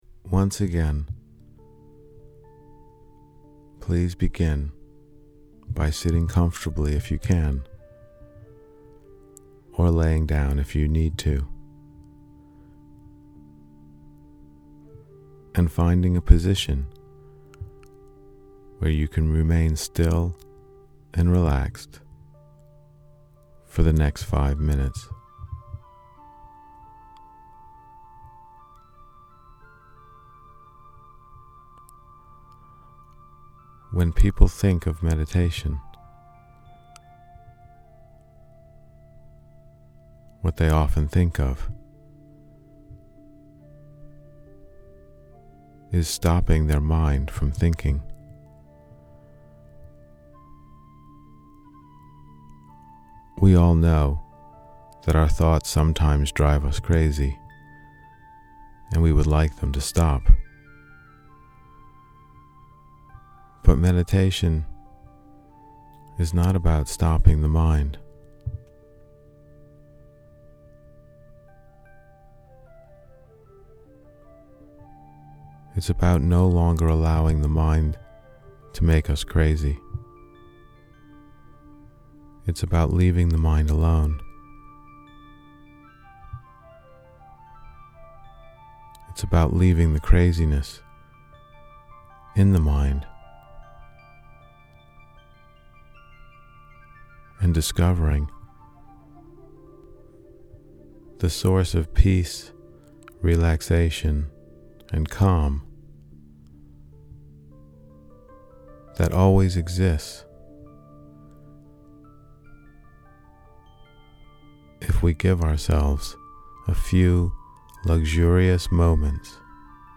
Day 2 Guided Meditation